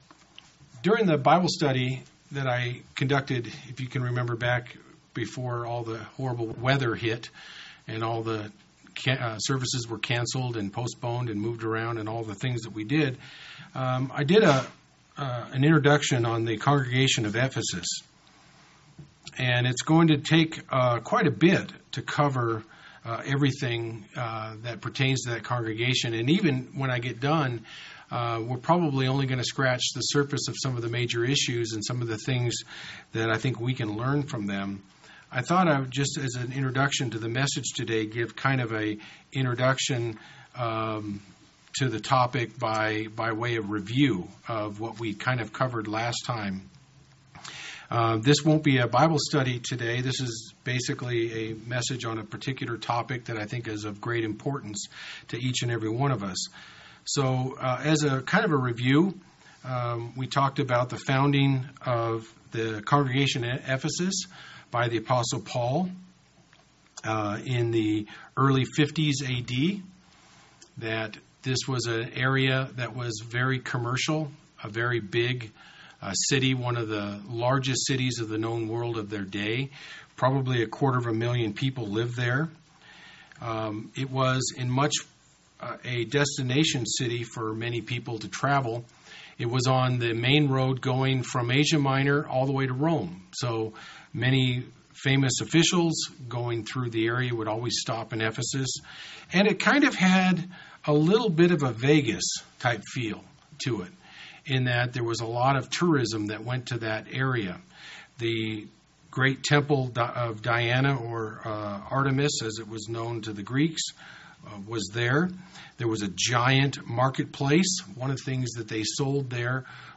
So instead of trying to expound on this topic during the bible Study, I would like to take this sermon topic from within the study and cover it independently.